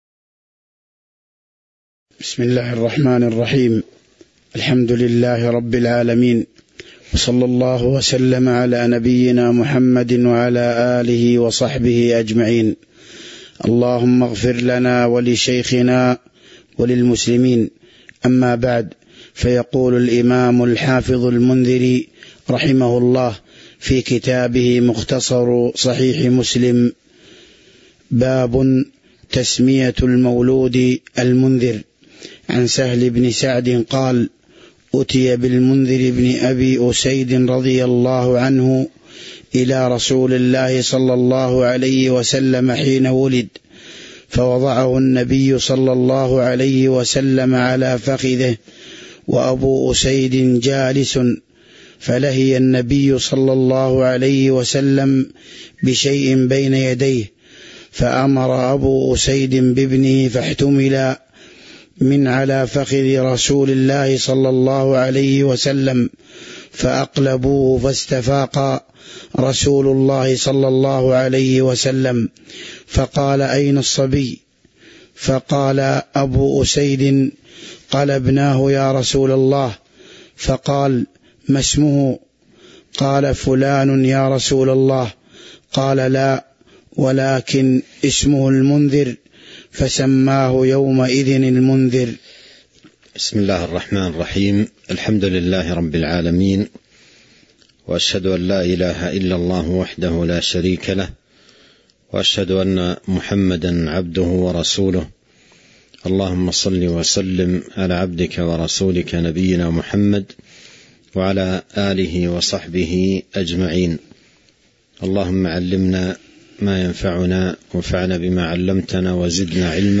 تاريخ النشر ٩ رجب ١٤٤٣ هـ المكان: المسجد النبوي الشيخ